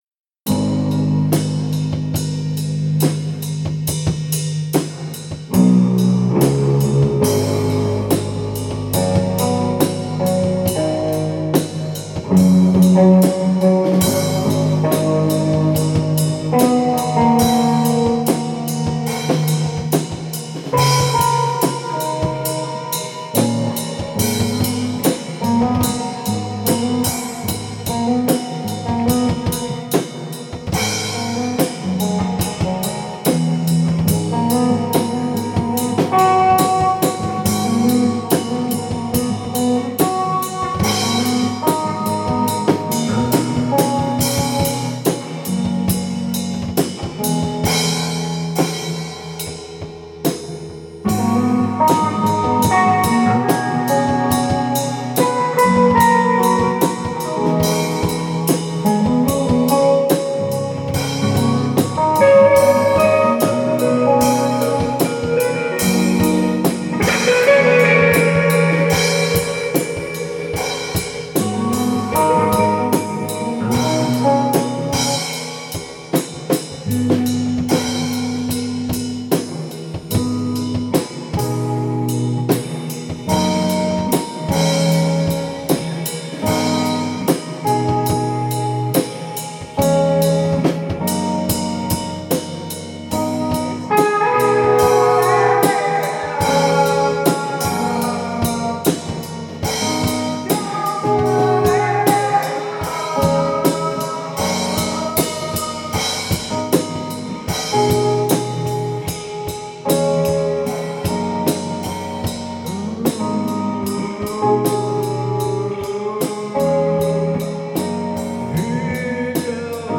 where: Marsonic
Jam